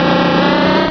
Cri de Dardargnan dans Pokémon Rubis et Saphir.